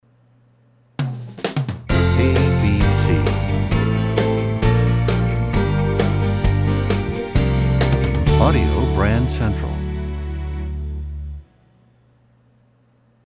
Genre: Instrumental.